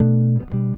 gtr_11.wav